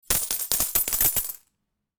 minigame_win.mp3